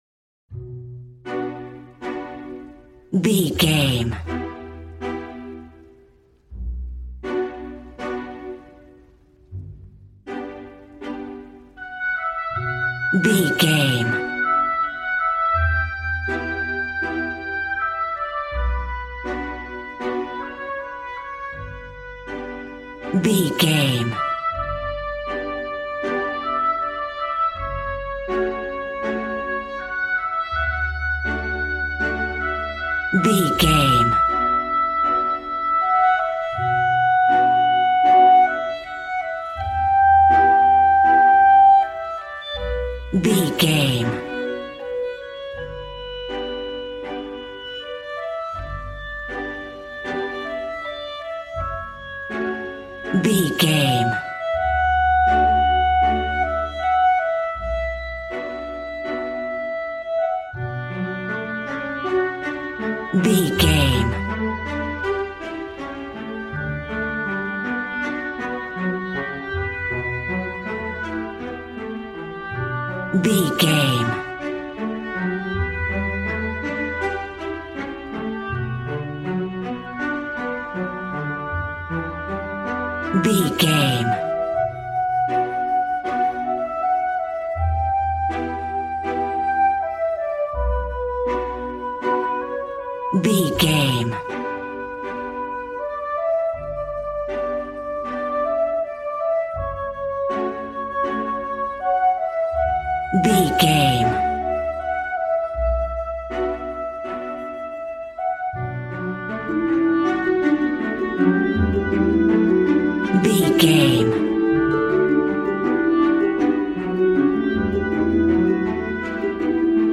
A warm and stunning piece of playful classical music.
Regal and romantic, a classy piece of classical music.
Aeolian/Minor
G♭
regal
piano
violin
strings